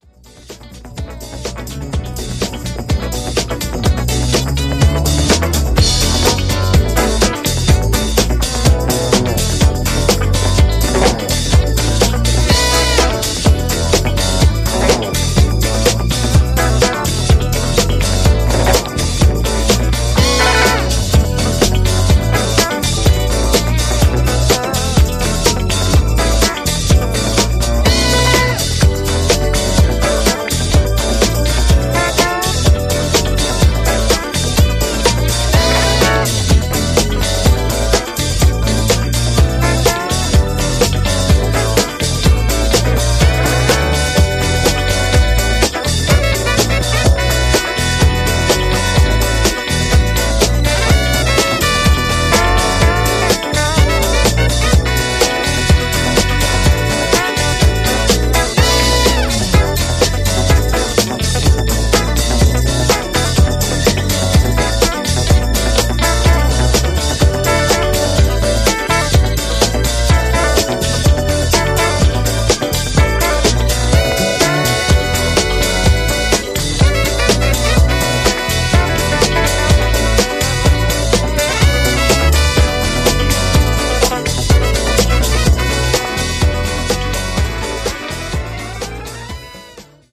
Three killer disco classics re-worked by a legend.